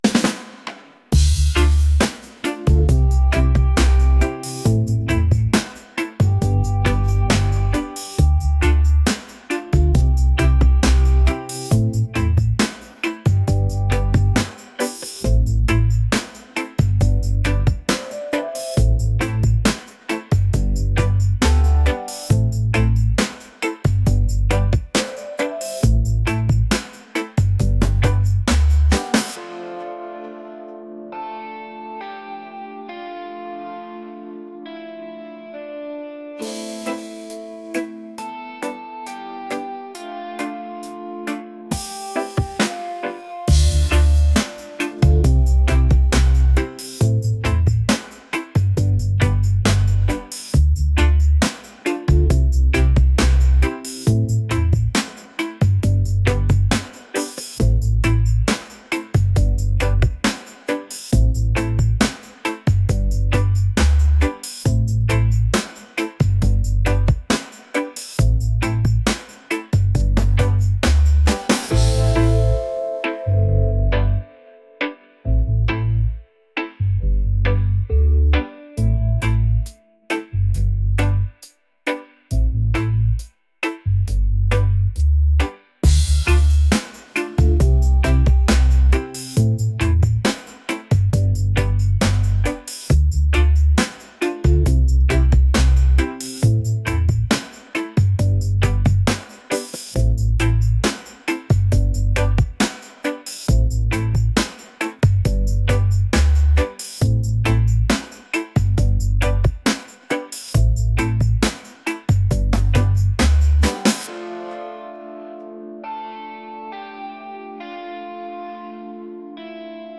laid-back | energetic | reggae